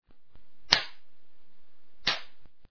Для сравнения звука выстрела из винтовки с модератором и без модератора:
Да, второй звук более глухой.